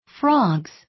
発音記号・読み方
/frɑgz(米国英語), frɑ:gz(英国英語)/